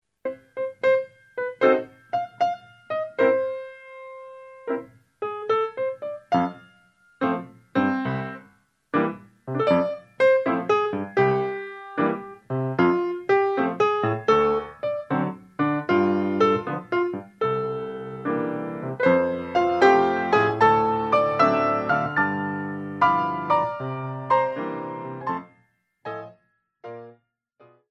These are original pieces in a classical style.
2/4 blues